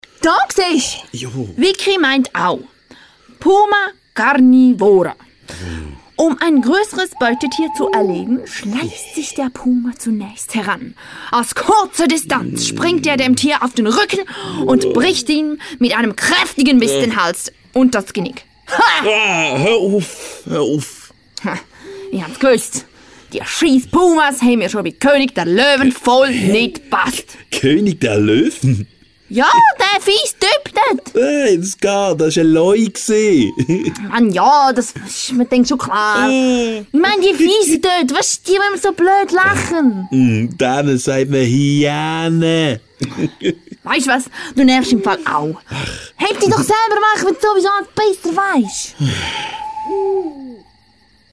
Sprecherin, Synchronsprecherin, Schauspielerin, Stationvoice, Moderatorin
Off Voice Doku